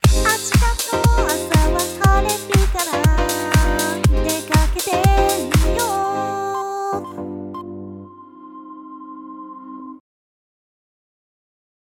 設定しているボーカルトラックのキャラクターに異なるボイスキャラクターの声質をミックスした声質が得られます。
ボイスミックスでキャラクターを替えたサンプルがこちらです。